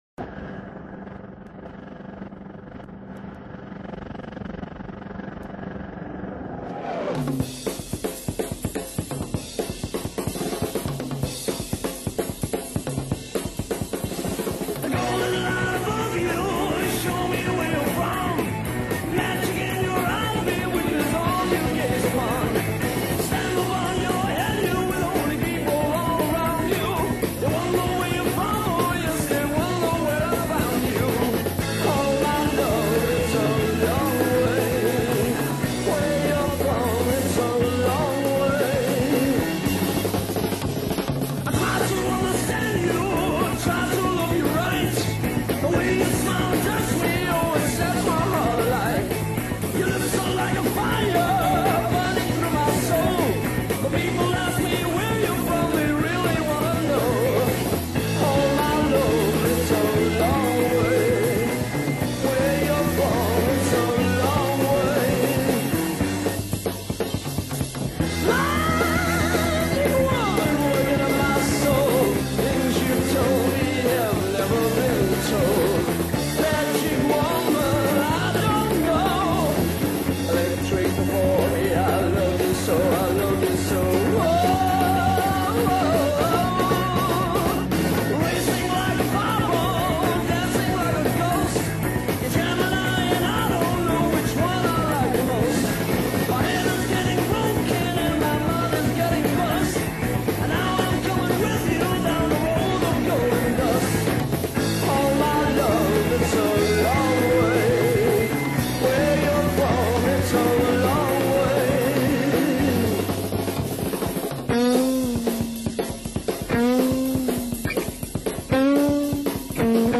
guitar
drums
keyboards
bass
vocals